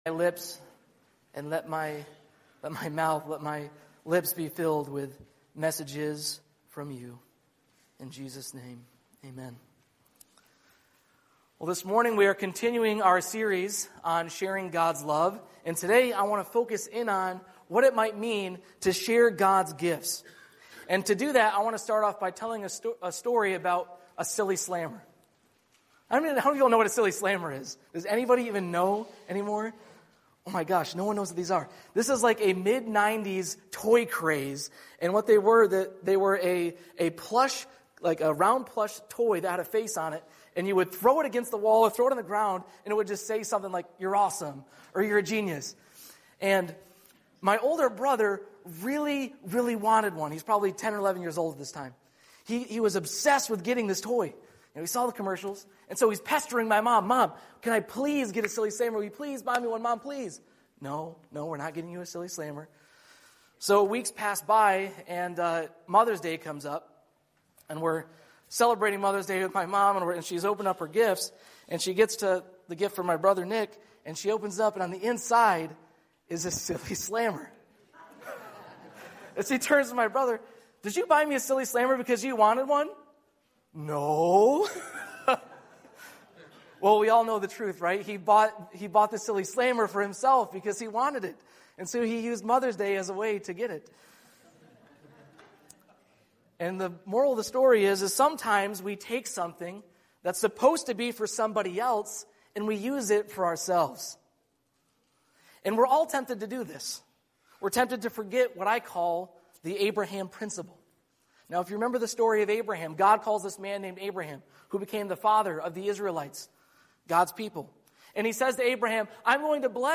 Sermon Series – Living on Purpose: Sharing God’s Love